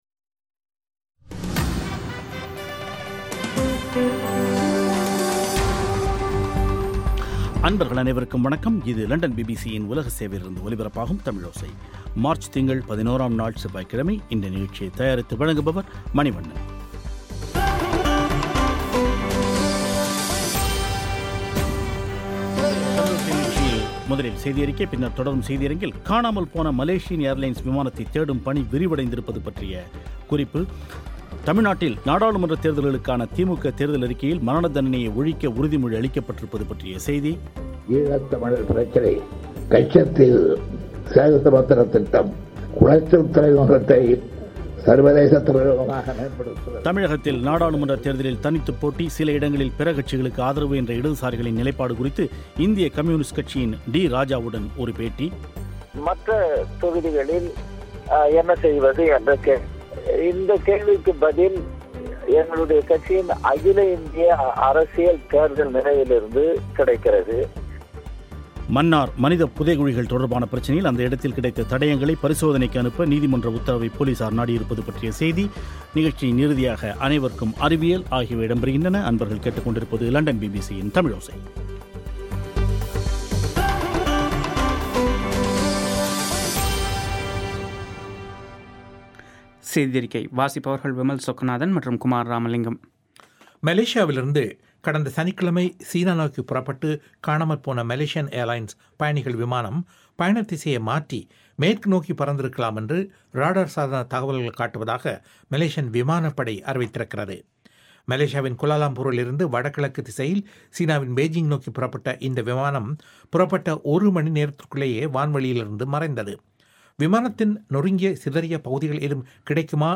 இந்தியக் கம்யூனிஸ்ட் கட்சியின் டி.ராஜாவுடன் ஒரு பேட்டி